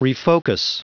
Prononciation du mot refocus en anglais (fichier audio)
Prononciation du mot : refocus